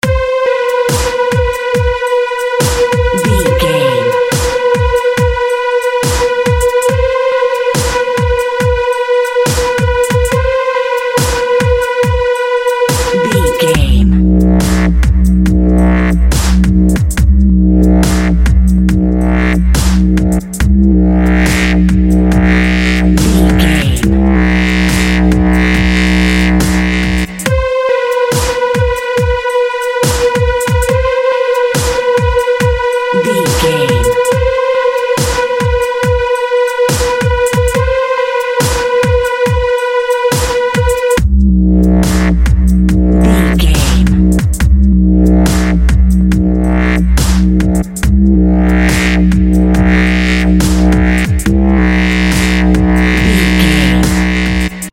Chilling Dubstep.
Fast paced
Phrygian
cool
groovy
futuristic
calm
repetitive
drum machine
synthesiser
ambient
electronic
downtempo
pads
strings
dark
glitch
synth lead
synth bass